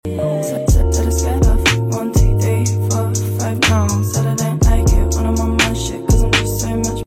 The Porsche 997 Carrera s. sound effects free download